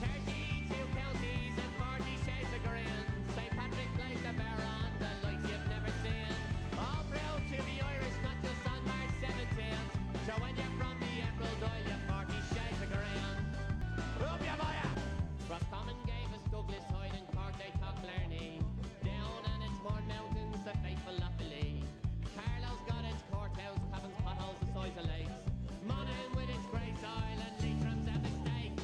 This is a sound sample from a commercial recording.
It is of a lower quality than the original recording.